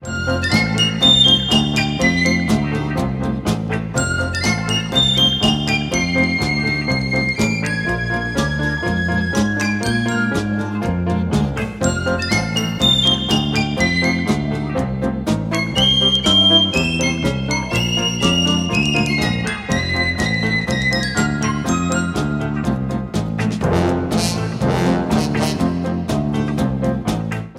• Качество: 128, Stereo
веселые
без слов
инструментальные
Флейта